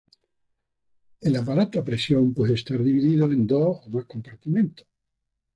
a‧pa‧ra‧to
Pronounced as (IPA)
/apaˈɾato/